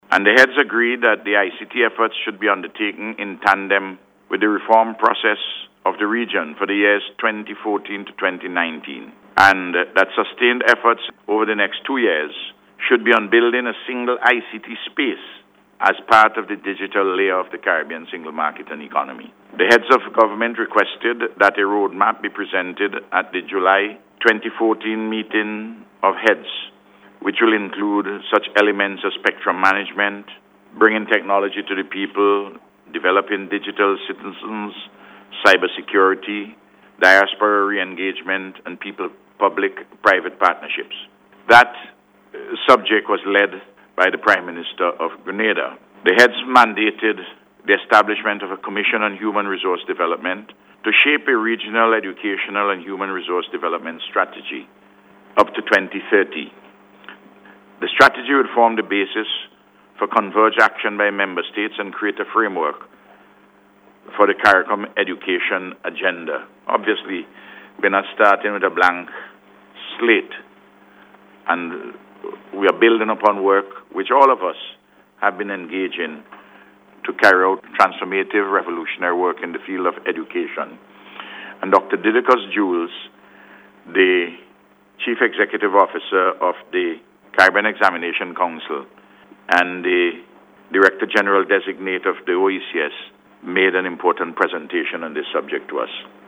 Speaking to Media Officials last night, CARICOM Chairman, and Prime Minister, The Hon Dr. Ralph Gonsalves said they had a very successful two-day summit, which dealt with a number of matters regarding the further development of the 15-member Regional Integration Grouping.